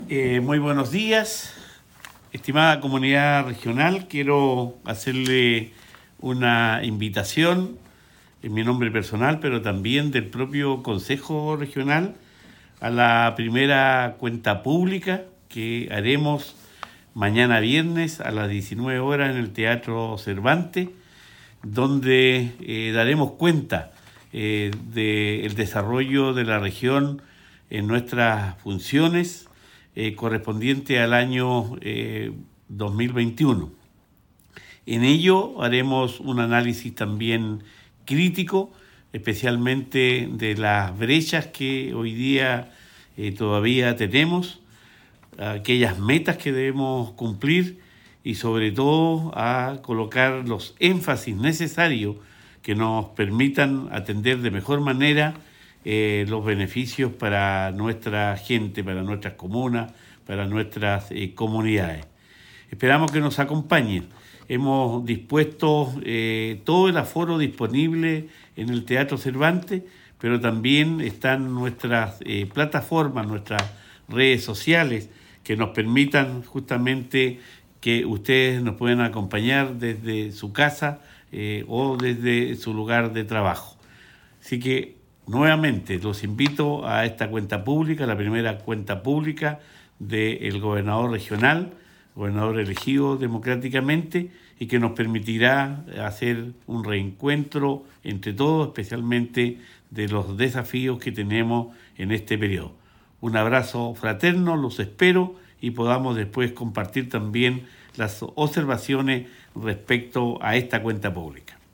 Gobernador-Cuvertino_Invitacion-Cuenta-Publica.mp3